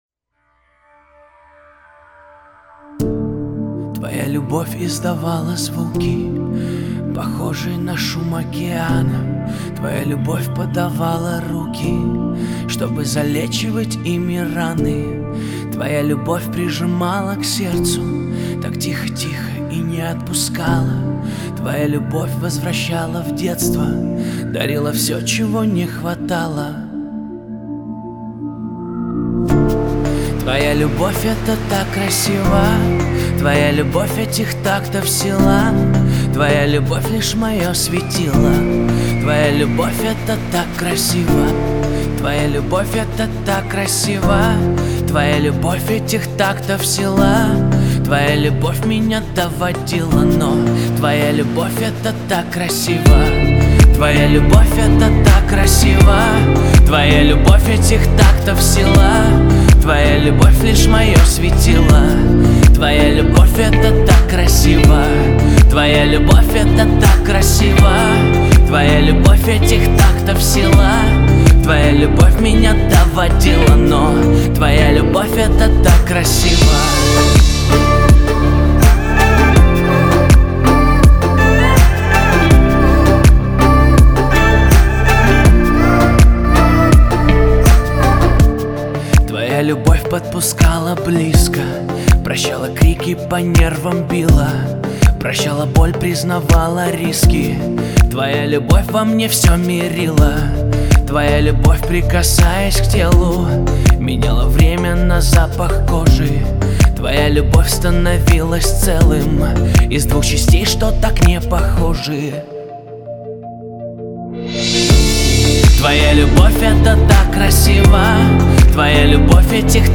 Аккорды. Вся песня аккорды: Dm F C A#